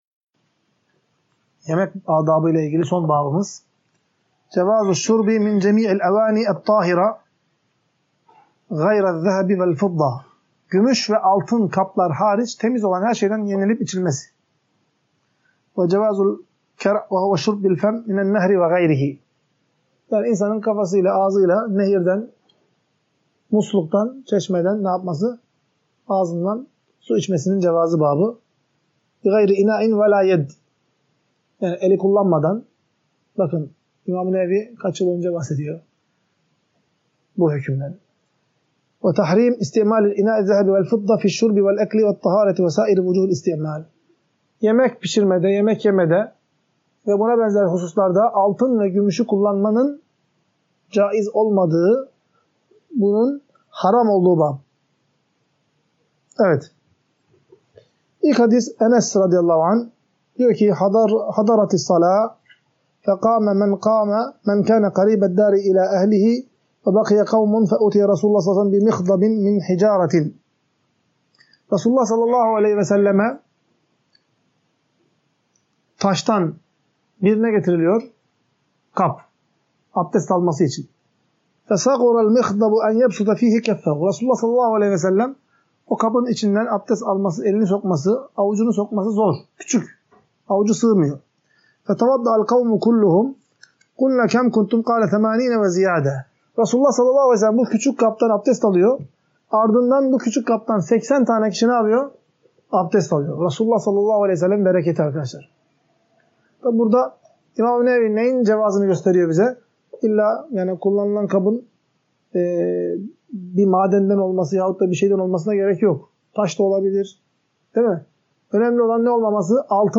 Ders - 17.